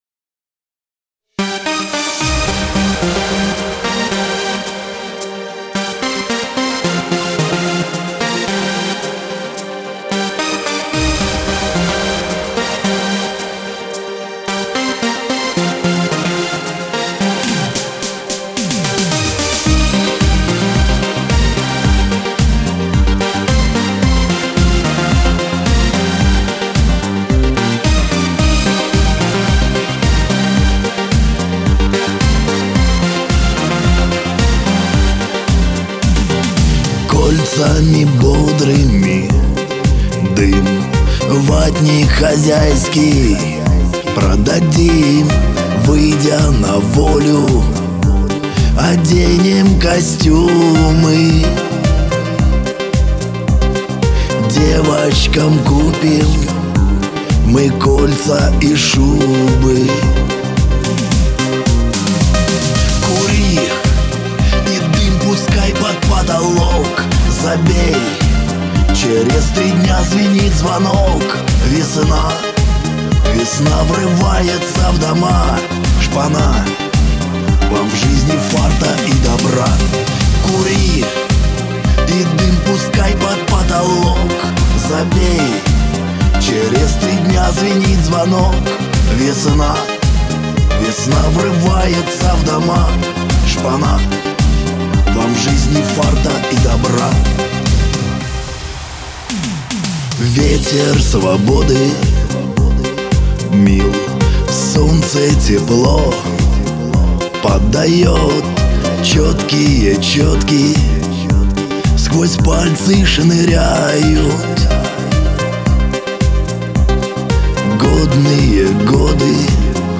Традиционный "колючий" шансон - почти все новое))